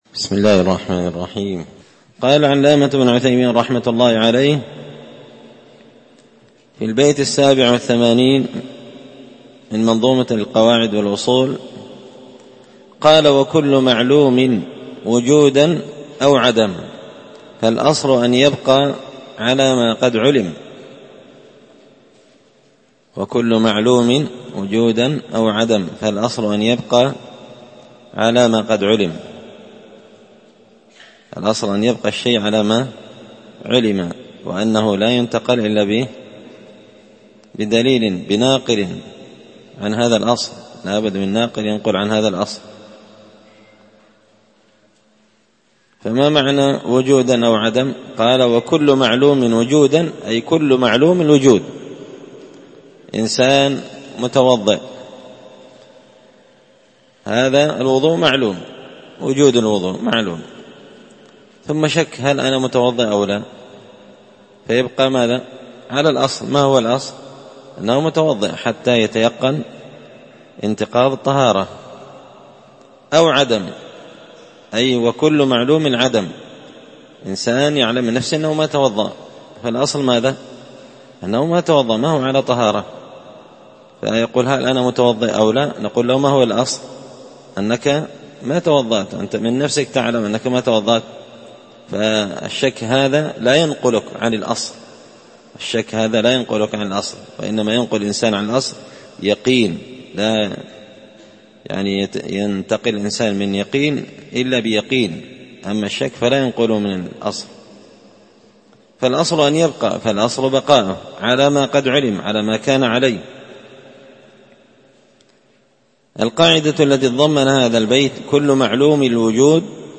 تسهيل الوصول إلى فهم منظومة القواعد والأصول ـ الدرس 51